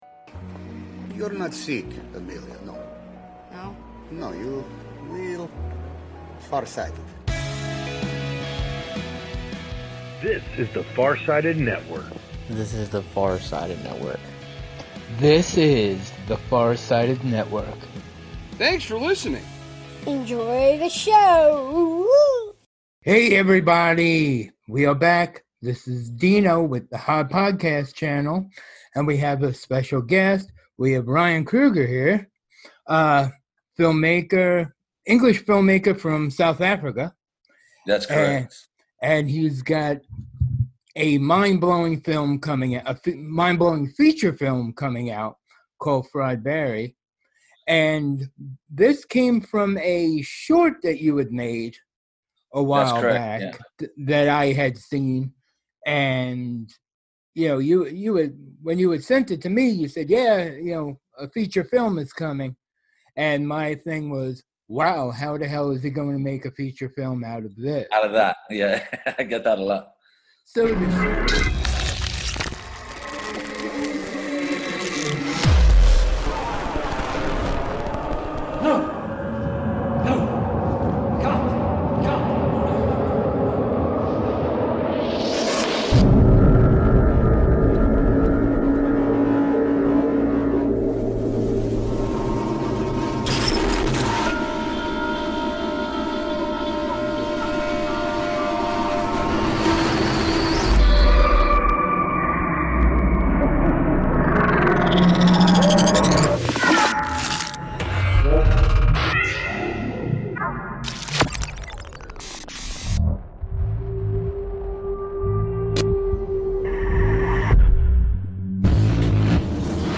The rebranded pod features raw and (mostly) unedited interviews, both new and not-so-new.